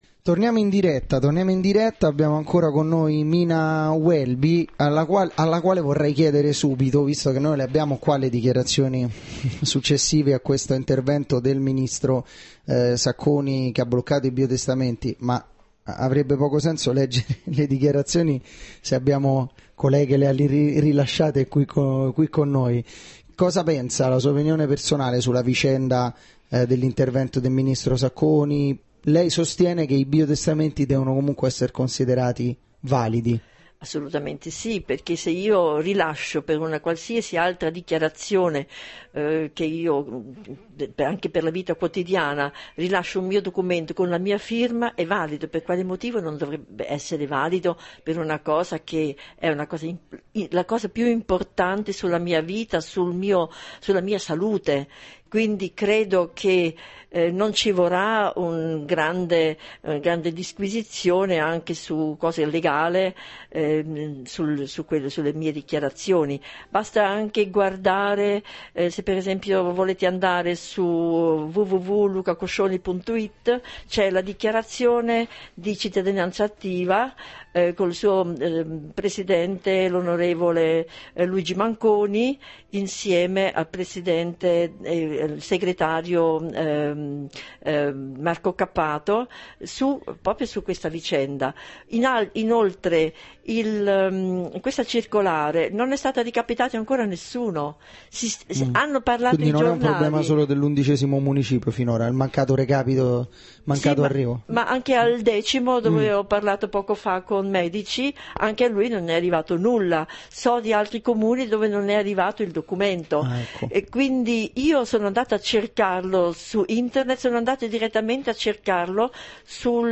Intervento in studio Mina Welby del 23/11/2010 - seconda parte